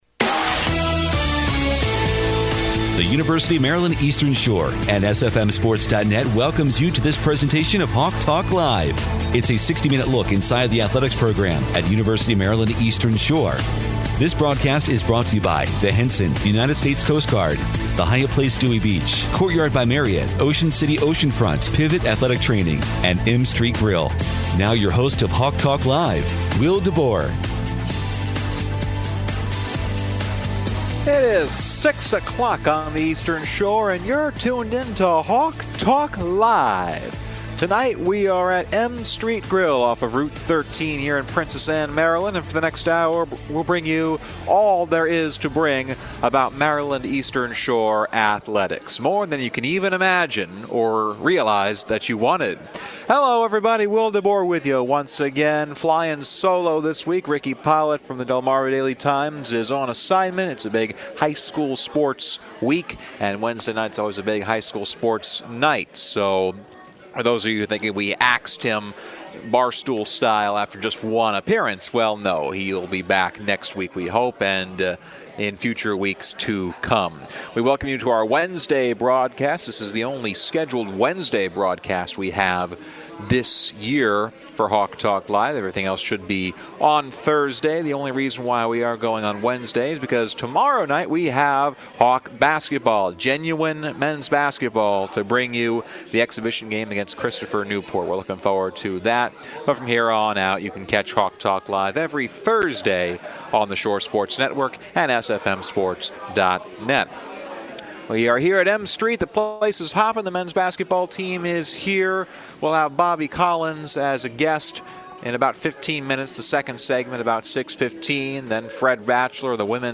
from M-Street Grill in Princess Anne